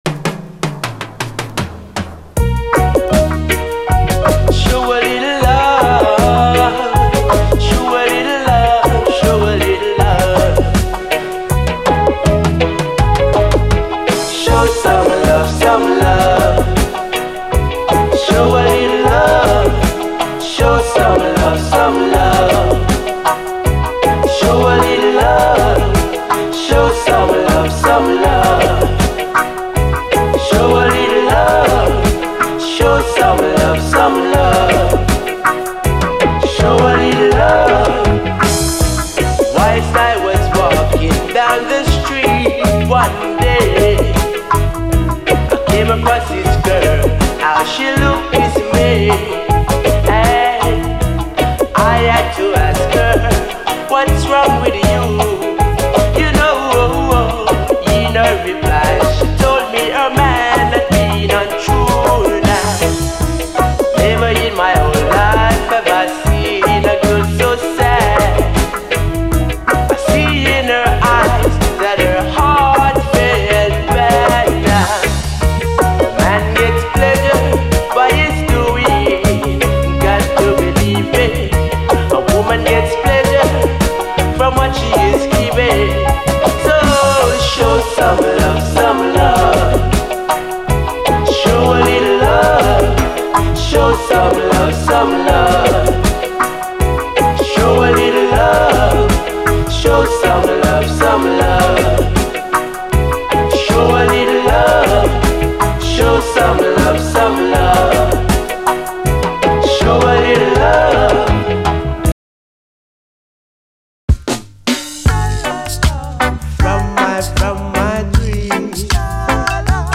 REGGAE
トロけるような最高UKラヴァーズ・ダブル・サイダー！ピュンピュン、グニョグニョとうごめくシンセ使い！